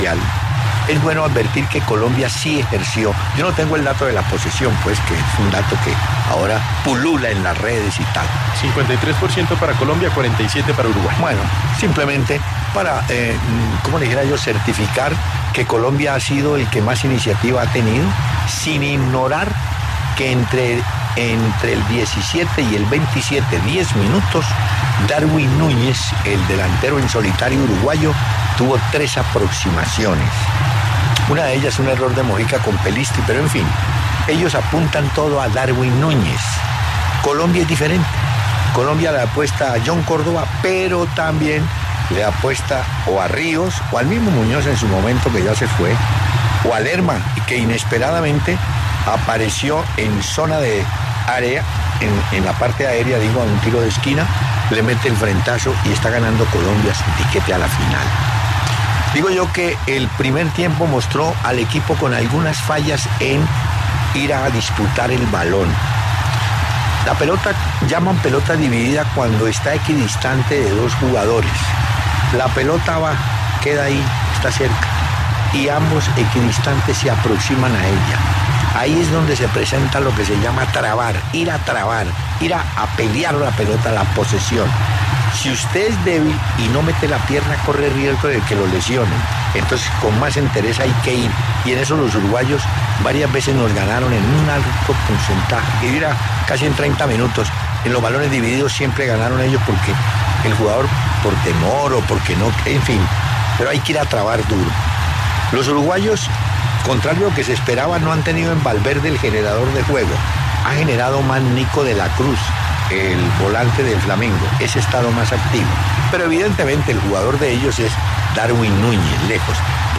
Hernán Peláez, reconocido periodista deportivo de W Radio, analizó el primer tiempo del partido entre colombianos y ‘charrúas’.